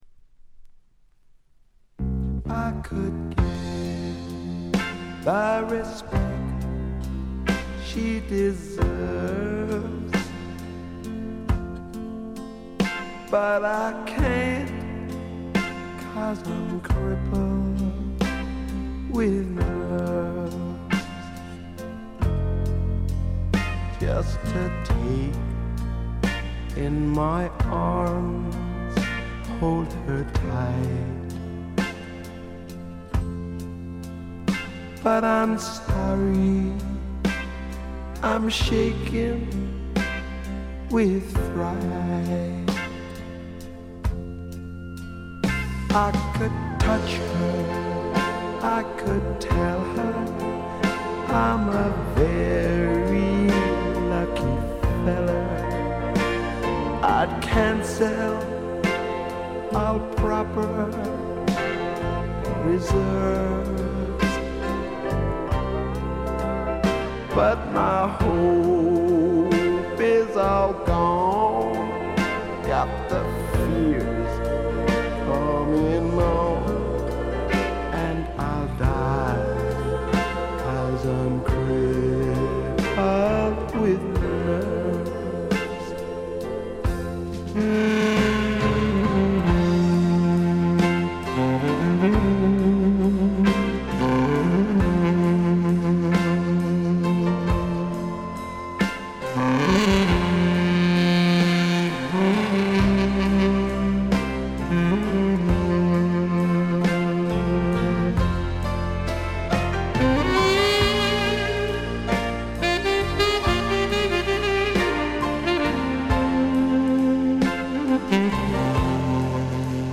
部分試聴ですがチリプチ少々。
後のブロックヘッズのような強烈な音もいいですが、本作のようなしゃれたパブロックも最高ですね！
試聴曲は現品からの取り込み音源です。